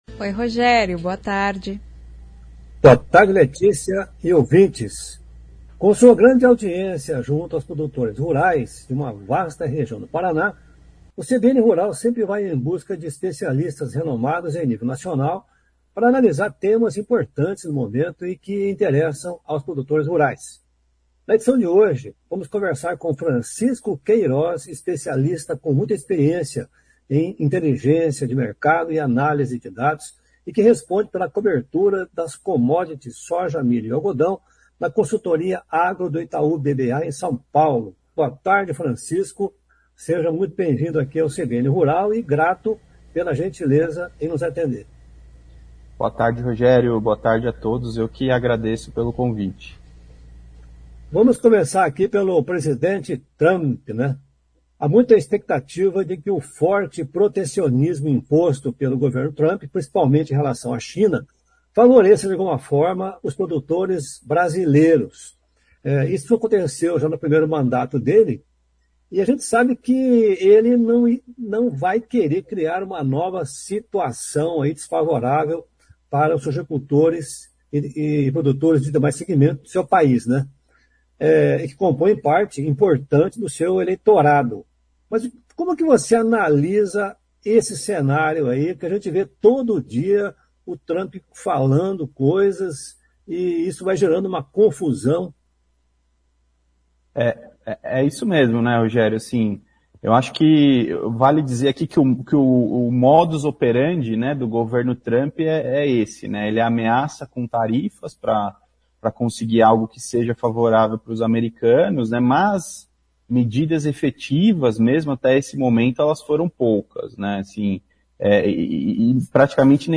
conversa com o especialista